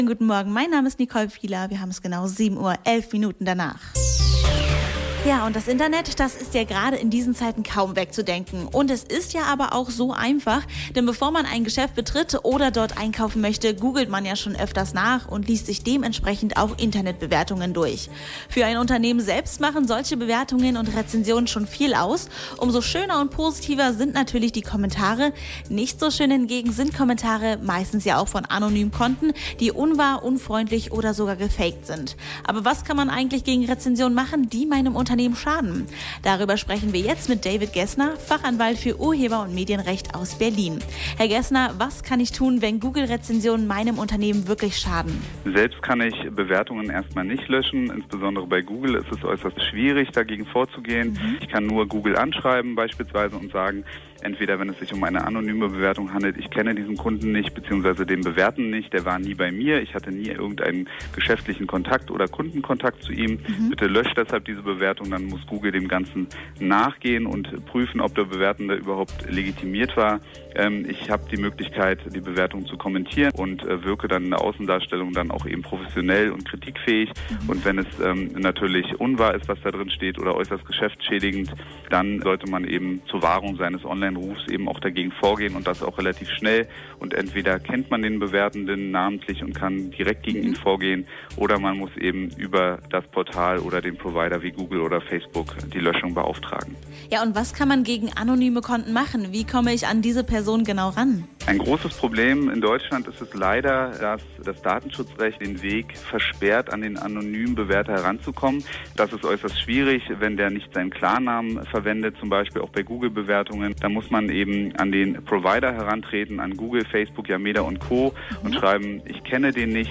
experteninterview-negative-bewertungen.mp3